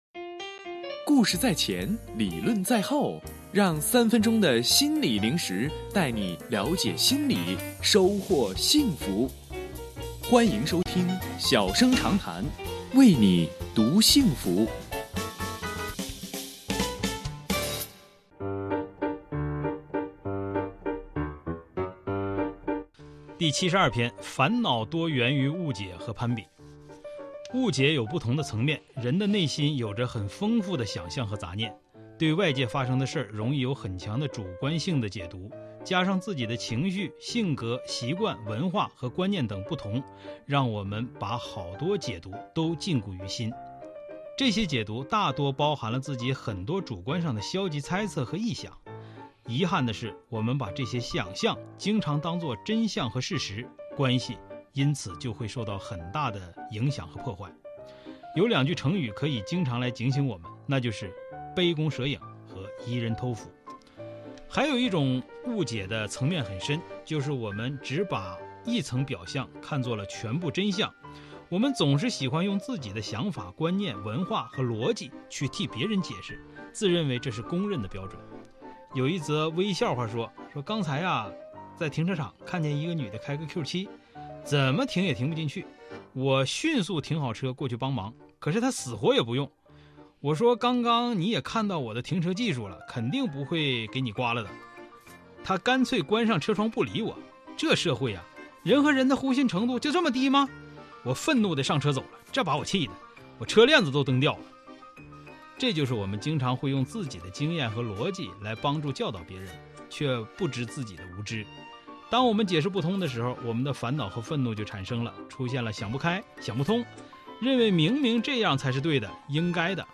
音频来源：吉林广播电视台 新闻综合广播